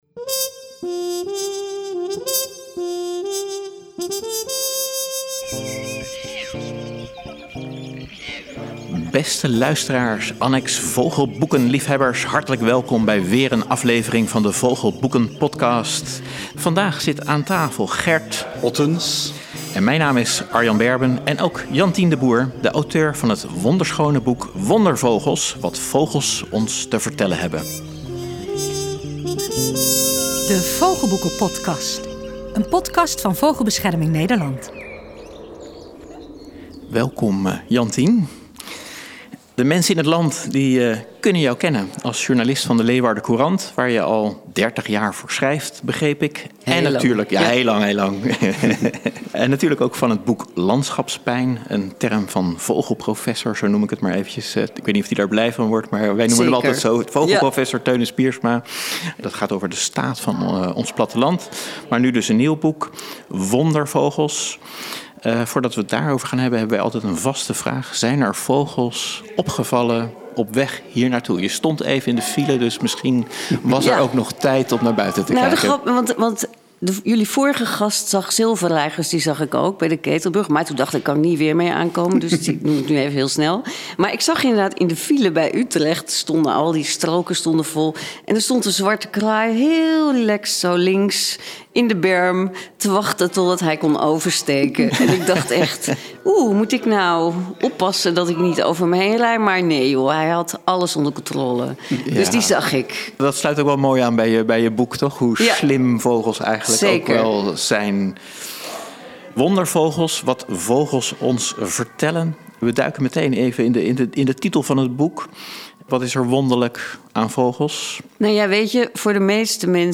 Luister naar een vrolijke doch ernstige aflevering van de Vogelboekenpodkast .